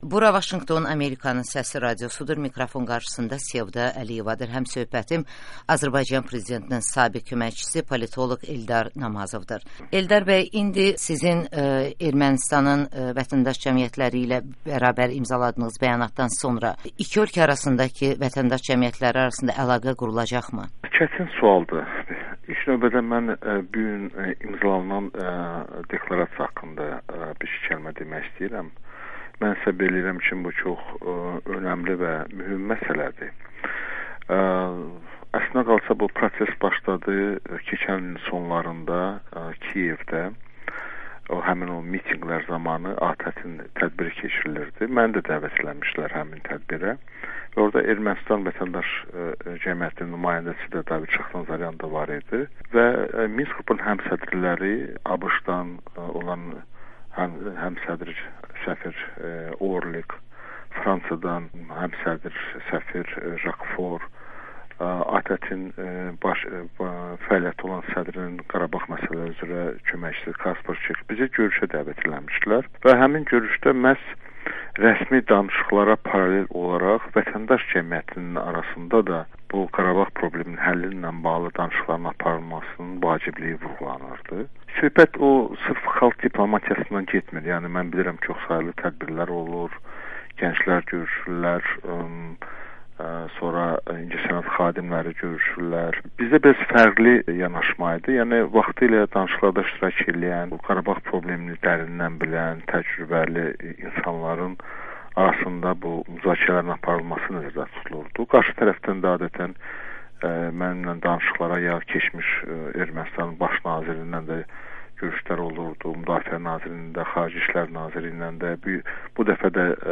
Eldar Namazovla müsahibə